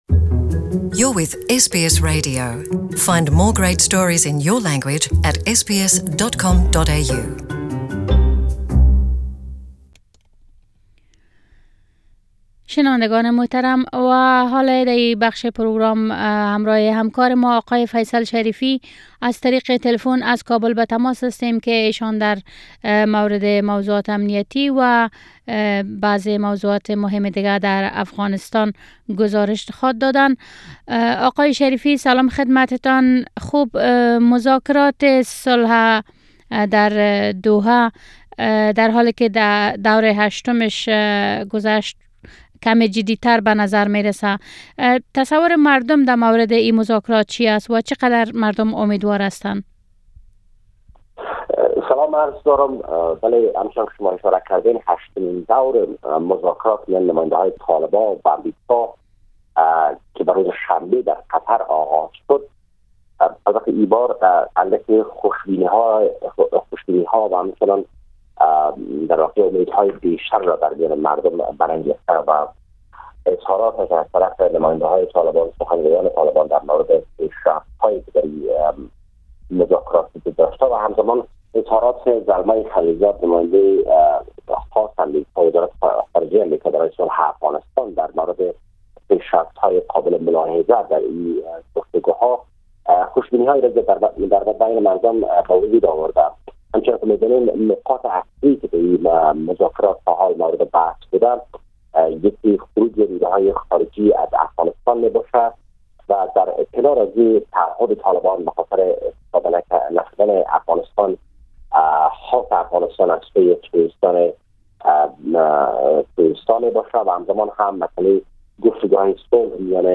Report From Afghanistan
Our reporter from Kabul on the recent developments in Afghanistan